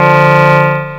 CARHORN.WAV